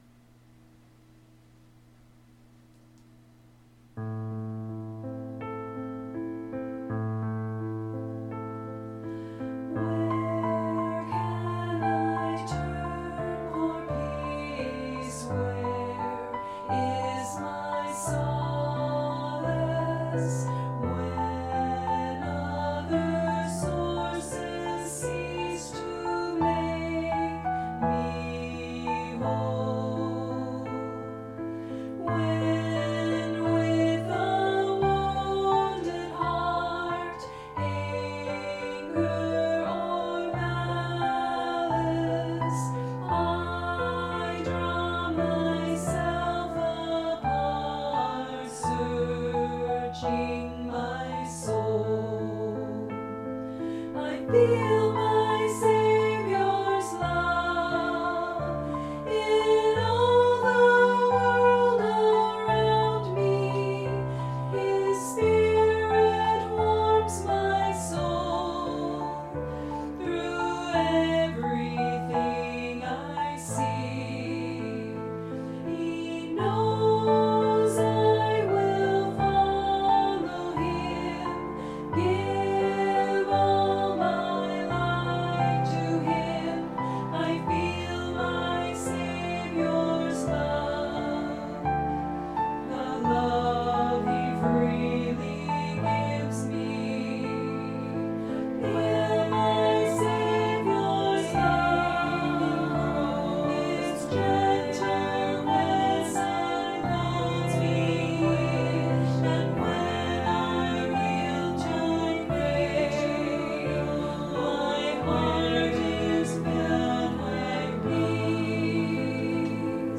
Voicing/Instrumentation: SSAA , Duet